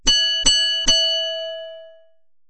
CableCarBell.wav